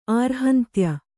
♪ ārhantya